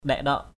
/ɗɛʔ-ɗɔʔ/ (d.) dáng điệu = maintien, port, tenue = carriage, figure. ngap ndaik-ndaok ZP =QK-_Q<K làm dáng = se faire beau. perk up. kumei ni hu ndaik-ndaok siam k~m] n}...
ndaik-ndaok.mp3